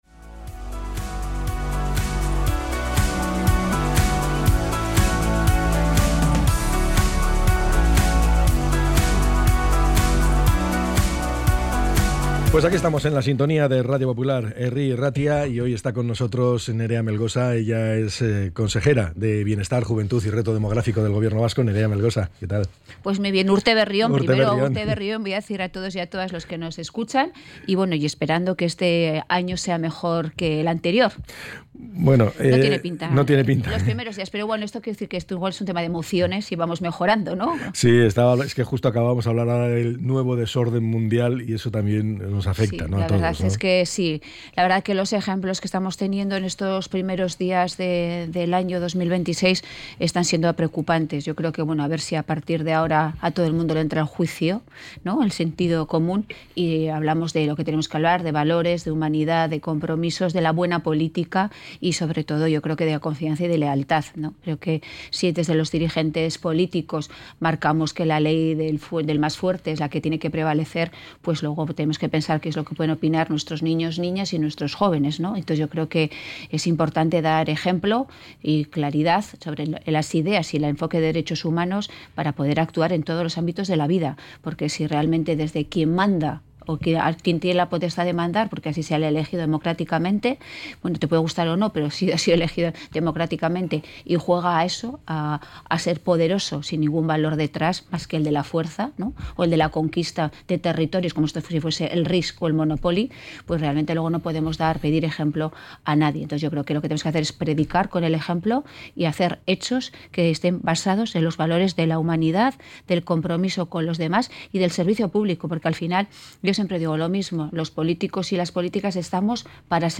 ENTREV.-NEREA-MELGOSA.mp3